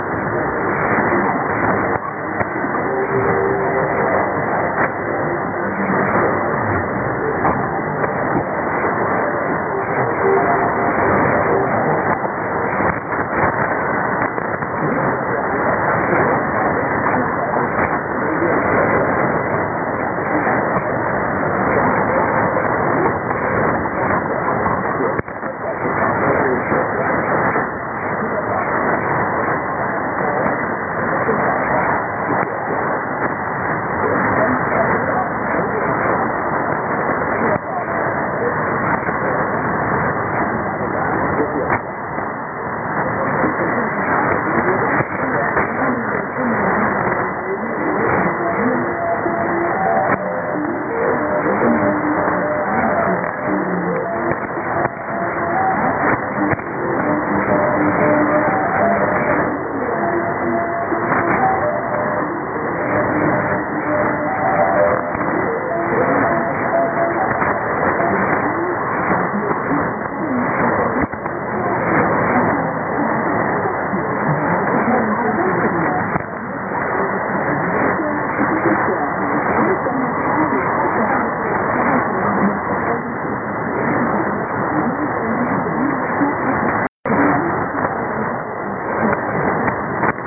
・このＨＰに載ってい音声(ＩＳとＩＤ等)は、当家(POST No. 488-xxxx)愛知県尾張旭市で受信した物です。
IS: interval signal